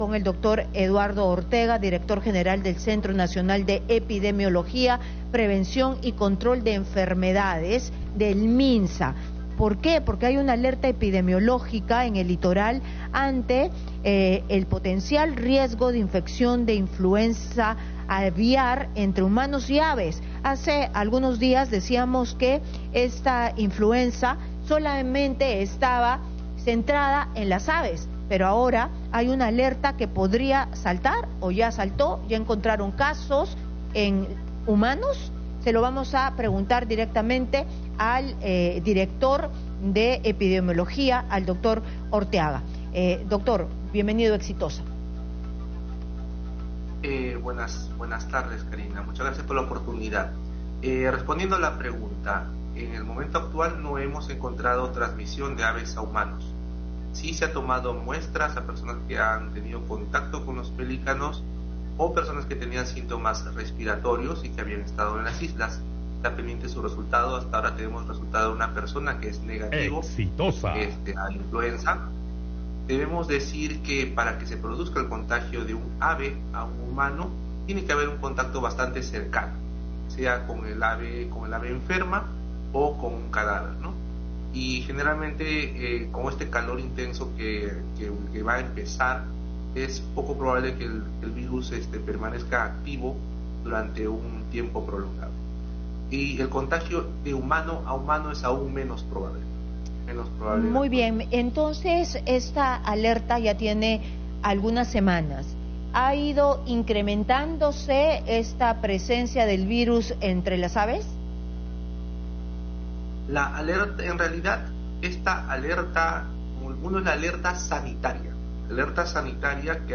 Entrevista a Eduardo Ortega - director general del CDC Minsa